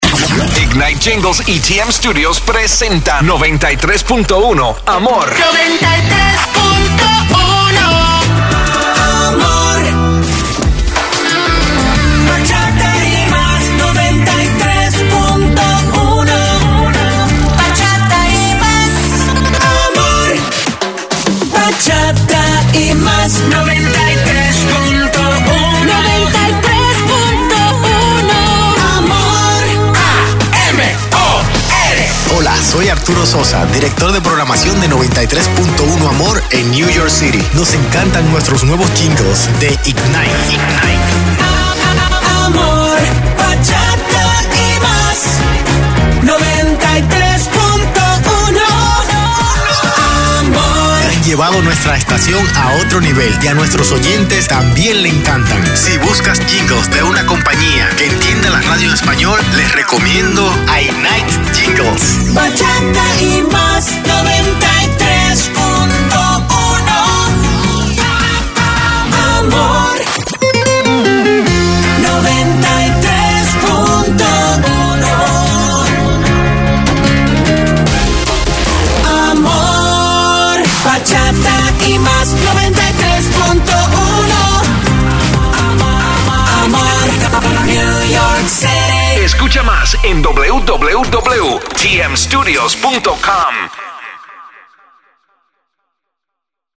Multiple Latin Genres